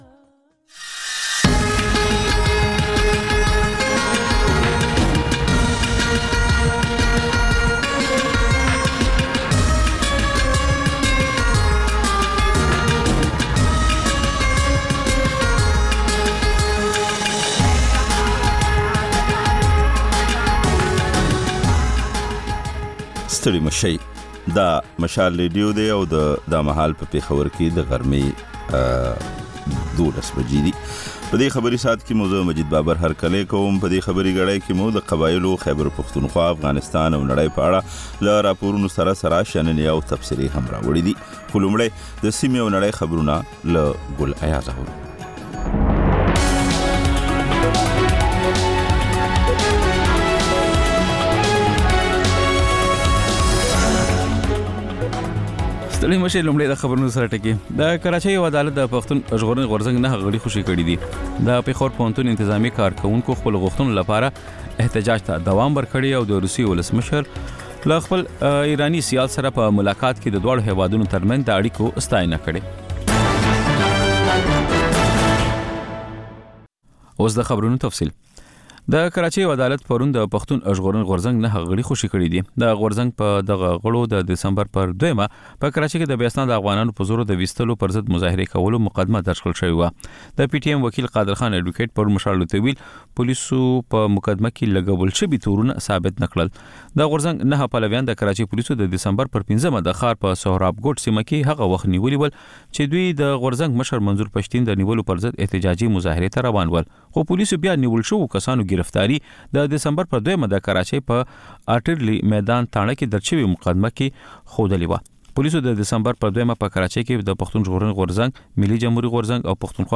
د مشال راډیو د نهه ساعته خپرونو لومړۍ خبري ګړۍ. په دې خپرونه کې تر خبرونو وروسته بېلا بېل سیمه ییز او نړیوال رپورټونه، شننې، مرکې، رسنیو ته کتنې، کلتوري او ټولنیز رپورټونه خپرېږي.